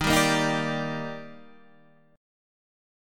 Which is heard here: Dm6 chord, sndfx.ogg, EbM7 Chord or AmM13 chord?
EbM7 Chord